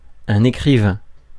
Ääntäminen
France: IPA: [ekʁivɛ̃]